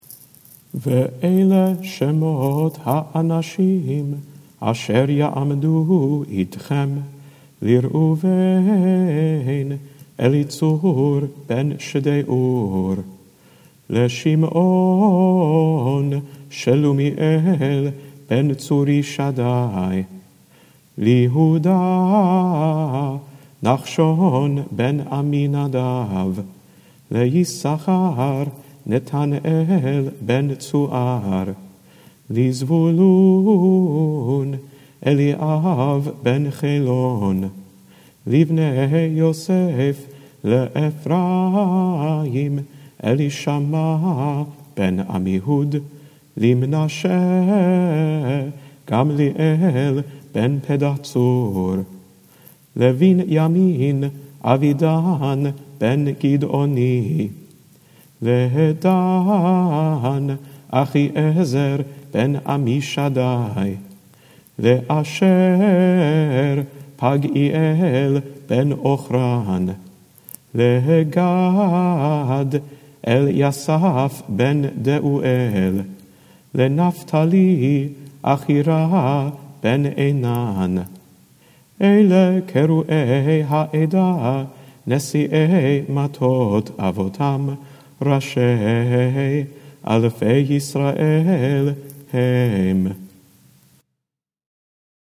Almost every verse calling out the name of an individual tribal leader (Rueben, Simeon, Judah, etc…) is punctuated by a trope called a Zakef Gadol.
That trope, the Zakef Gadol, is a triumphant exclamation, like jumping up the steps to the landing and then back down.
“These verses are shorter than most, but richly resonant with the zakef gadol trope: they rise, crest, and leave us leaning in to hear what comes next.